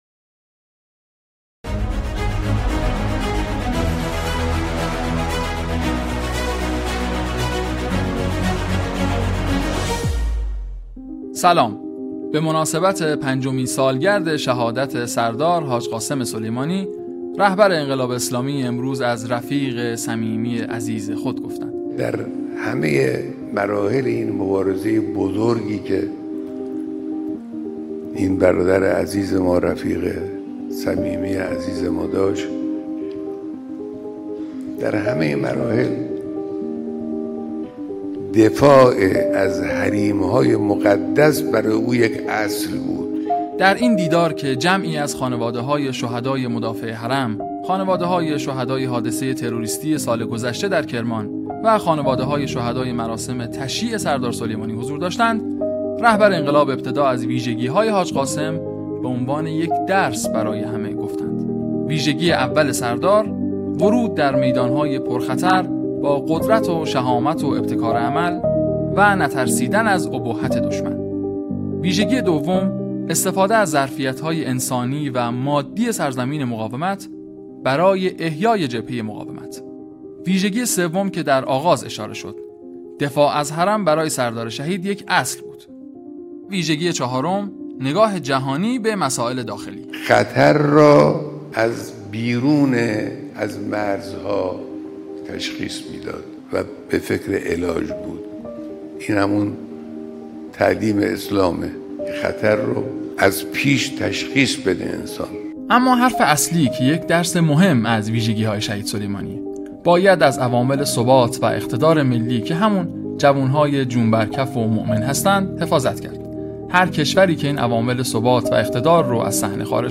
برچسب ها: بصیرت ، صدای انقلاب ، فاطمی نیا ، سخنرانی ، شهادت امام هادی (ع)